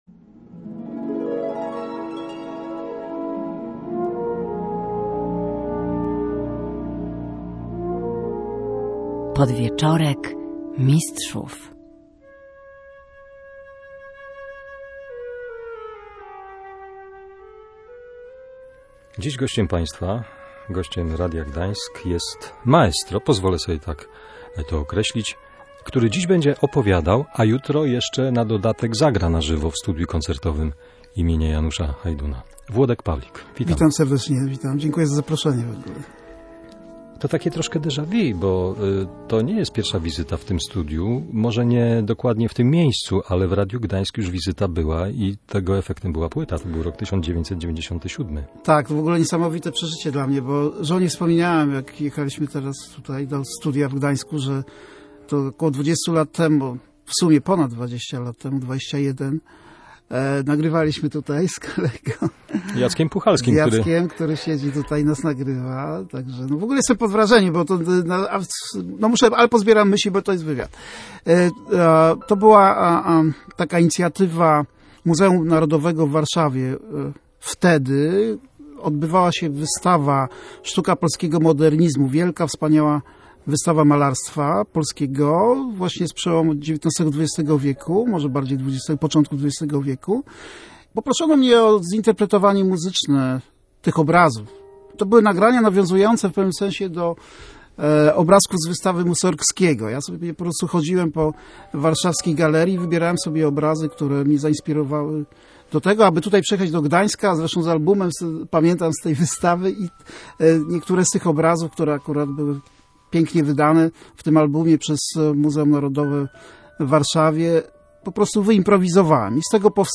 Pianista jazzowy Włodek Pawlik.